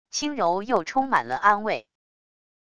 轻柔又充满了安慰wav音频